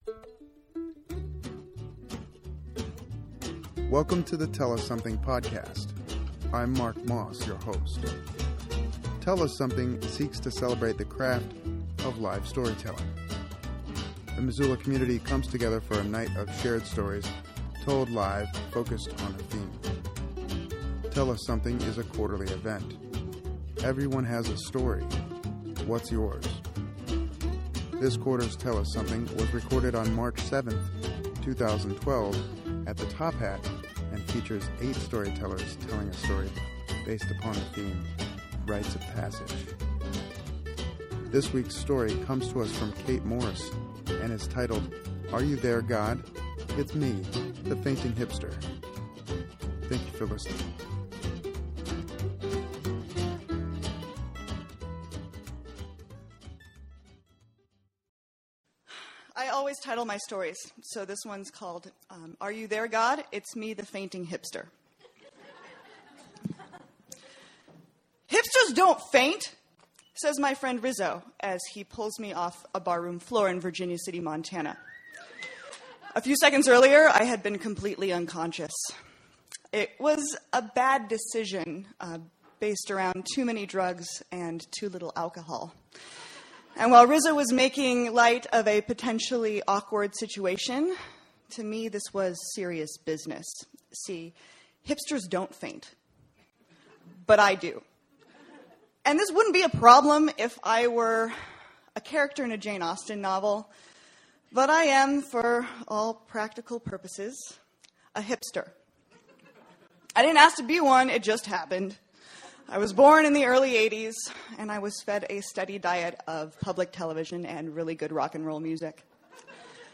This quarter’s Tell us Something was recorded on March 7th, 2012 at the Top Hat. The theme was “Rites of Passage”.